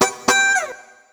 120FUNKY18.wav